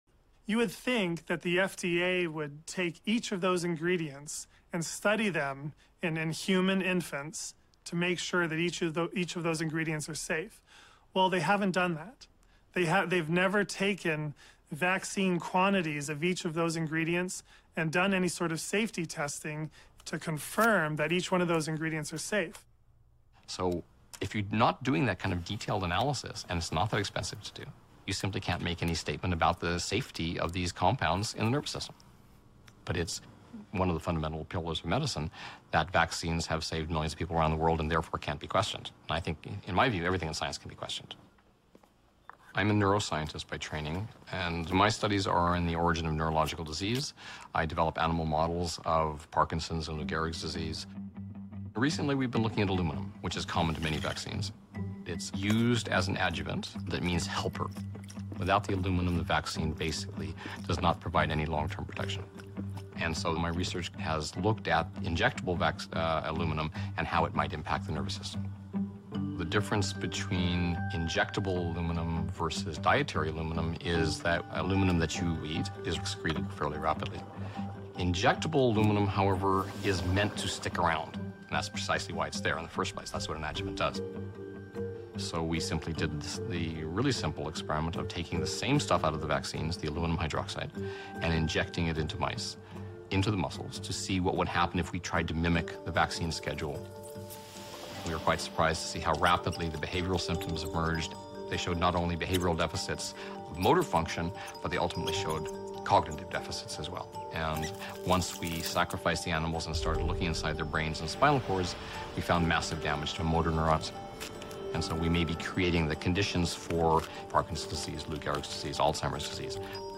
Doctors speak out against the use of Aluminium in vaccines the adverse effects and the lack of safety checks on the substance